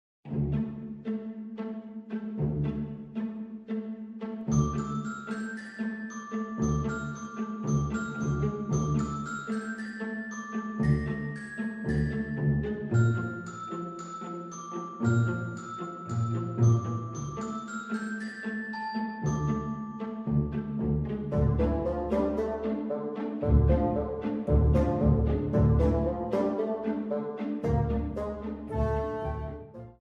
Trimmed to 30 seconds, with a fade out effect
Fair use music sample